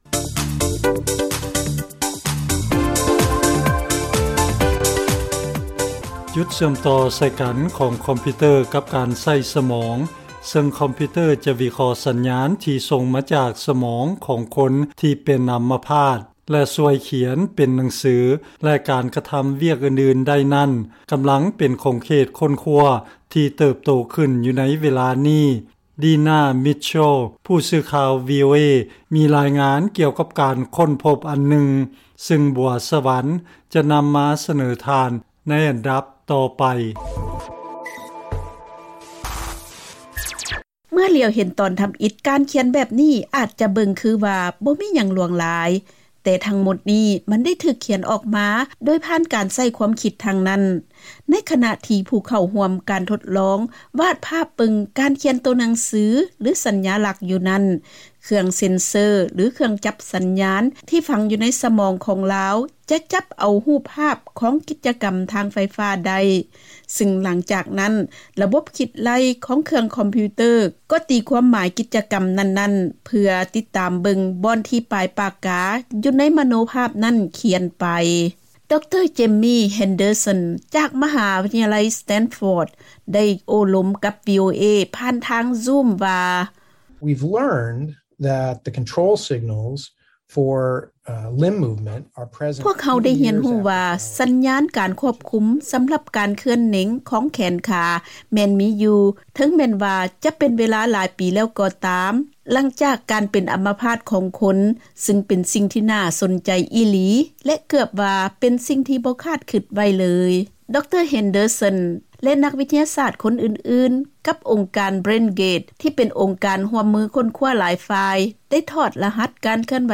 ເຊີນຟັງລາຍງານກ່ຽວກັບການໃຊ້ເທັກໂນໂລຈີຊ່ວຍຄົນເປັນອໍາມະພາດໃຊ້ສະໝອງແລະສື່ສານໄດ້ດີຂຶ້ນ